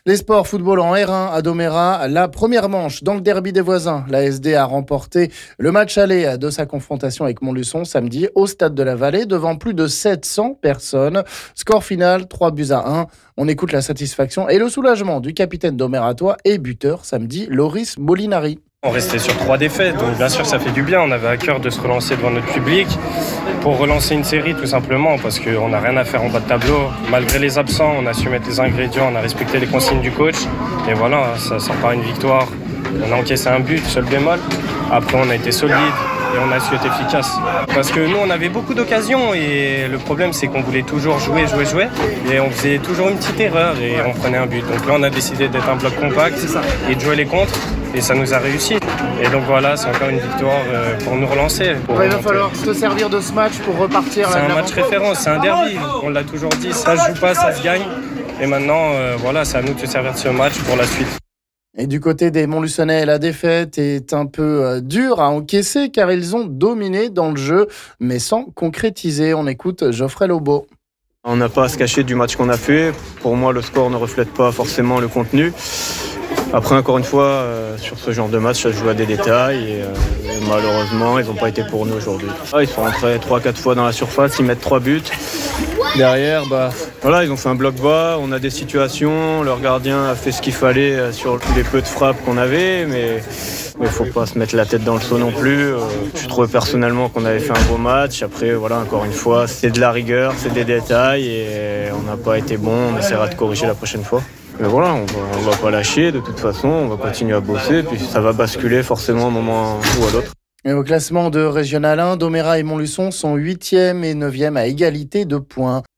On écoute ici les réactions des joueurs